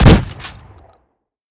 pump-shot-7.ogg